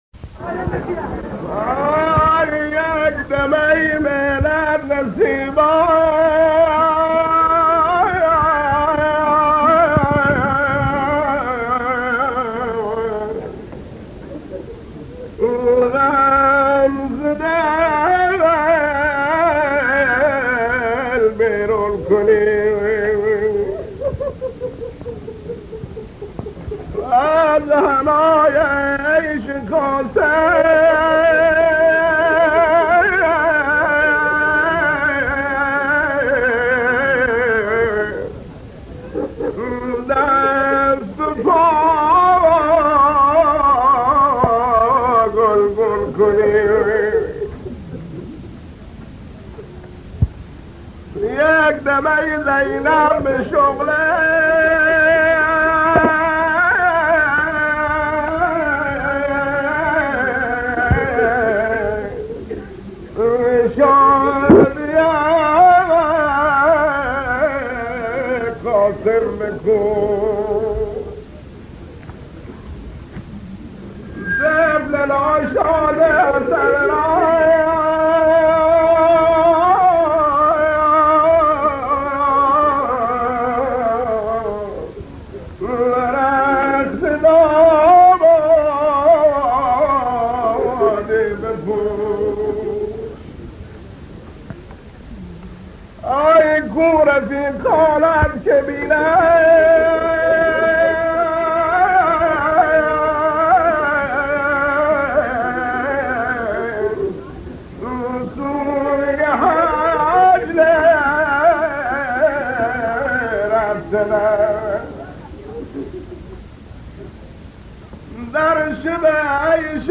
وبلاگ تخصصی نوحه های سنتی بوشهر
مرثیه شب عاشورا